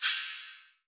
Index of /90_sSampleCDs/AKAI S6000 CD-ROM - Volume 3/Crash_Cymbal1/FX_CYMBAL
FX CYMB 1.WAV